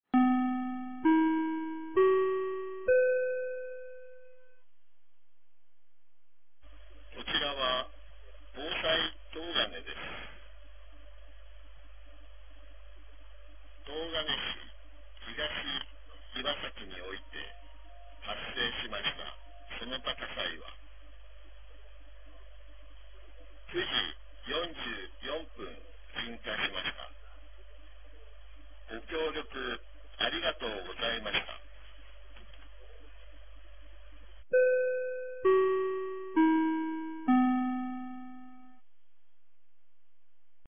2025年02月01日 09時46分に、東金市より防災行政無線の放送を行いました。